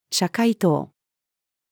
社会党-female.mp3